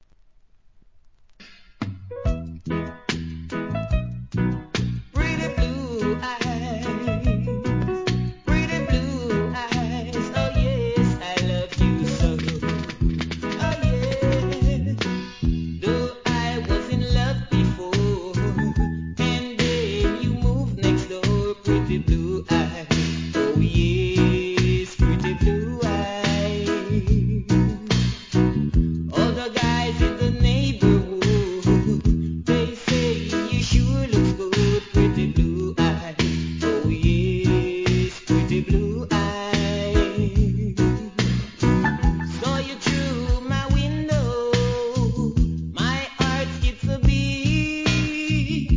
REGGAE
'80sのほのぼのTRACK